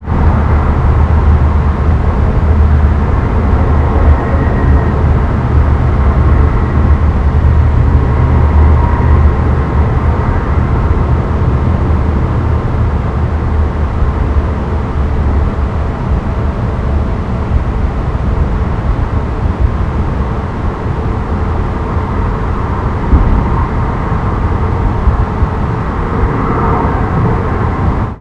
city_ambience01.wav